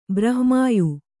♪ brahma'yu